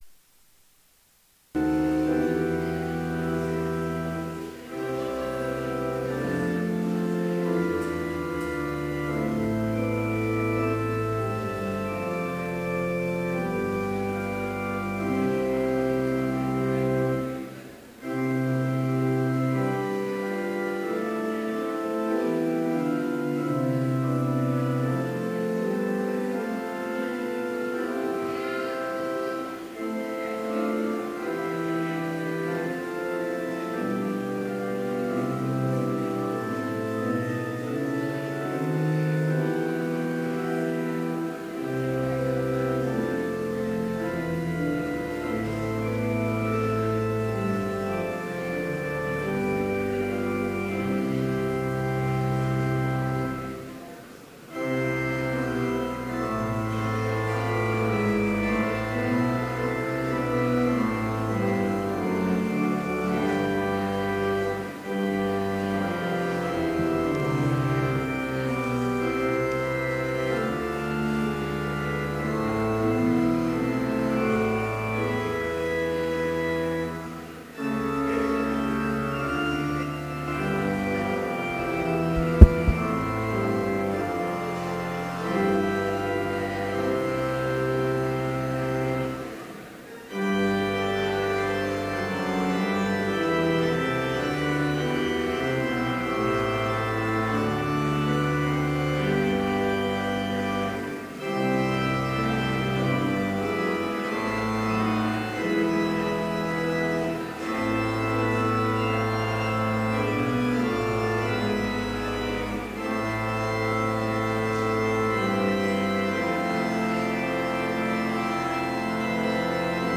Complete service audio for Chapel - September 29, 2015
Prelude: Lord, Let At Last Thine Angels Come J.S. Bach Hymn 547 Jesus, Brightness of the Father Versicles and Gloria patri (led by choir) The Office Hymn 545 Lord God, We All To Thee Give Praise (Everyone may be seated for the hymn.
Benedicamus and Benediction (led by the choir) Hymn 406:3 Lord, Let At Last Thine Angels Come Postlude: Concerto in A Minor, 1st mvmt A. Vivaldi